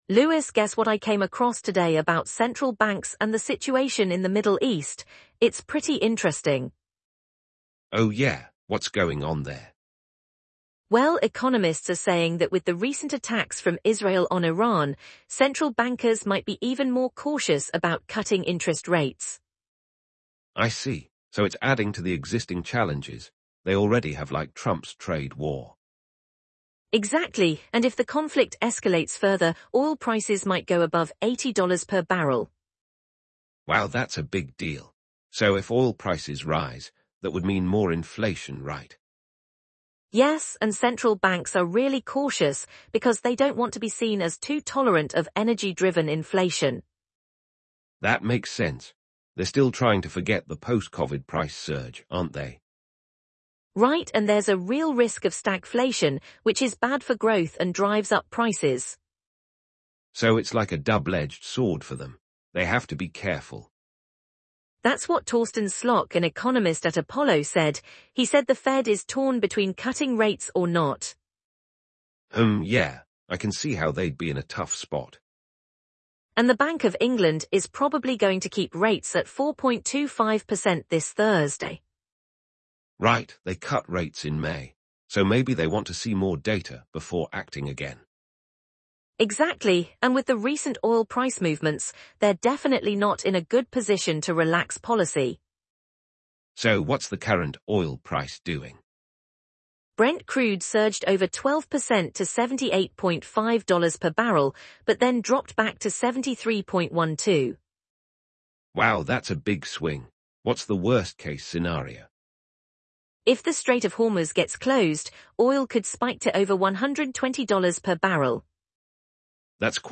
UK morning business news